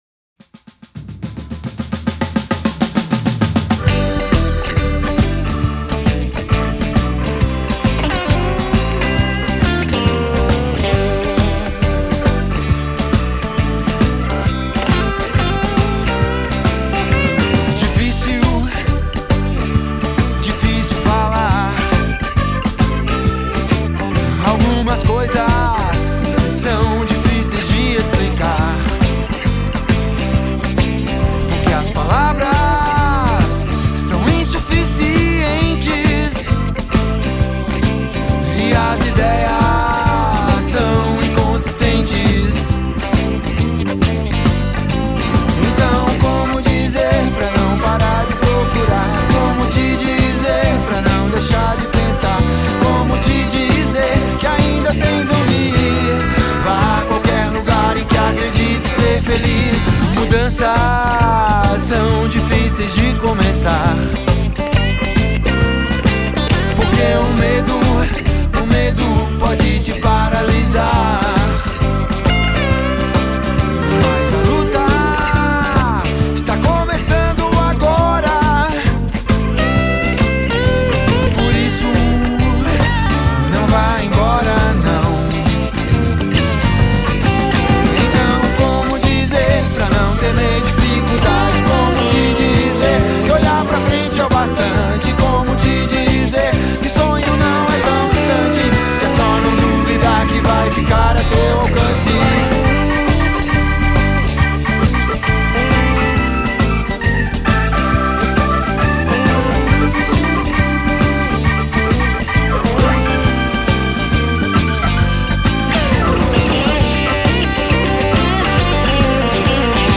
A música é um rock’n roll shuffle, no estilo Chicago.
Teclado